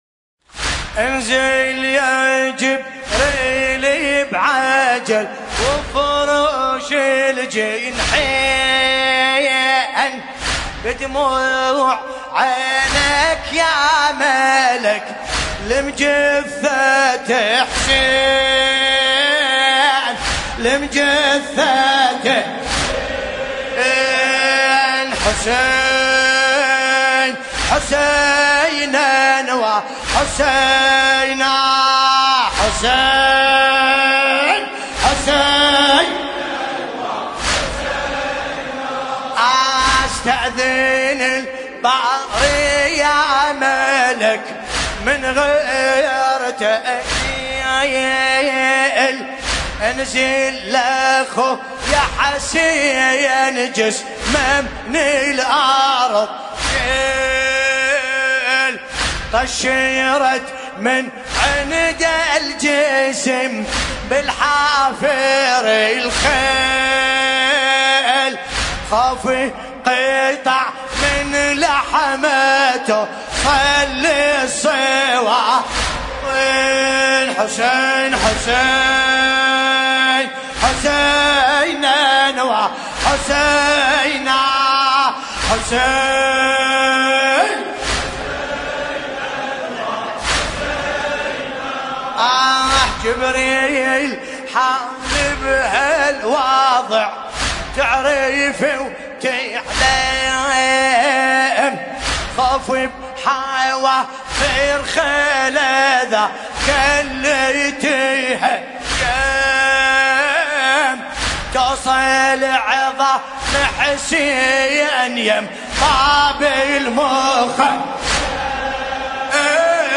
المناسبة : ليلة 10 محرم 1441 هـ